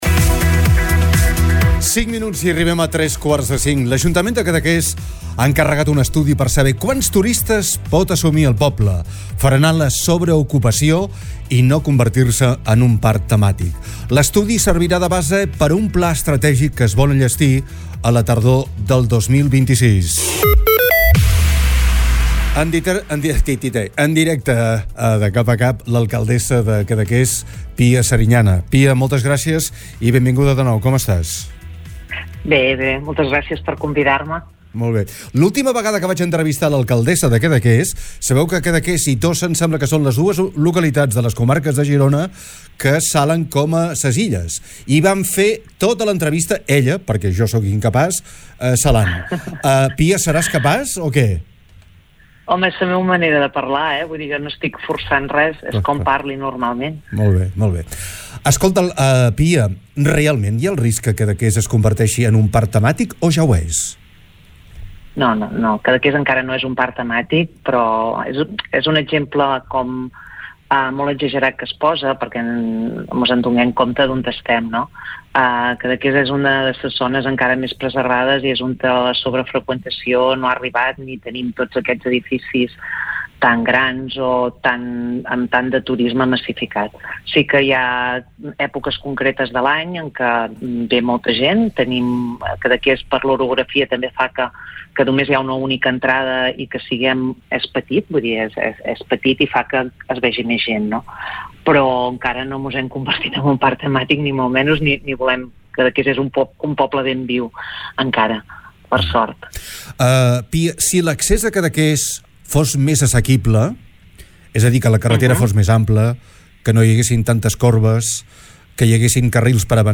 L’alcadessa, Pia Serinyana ha explicat a DE CAP A CAP  que l’objectiu és apostar per un turisme “sostenible i de qualitat”.
ALCALDESSA-CADAQUES.mp3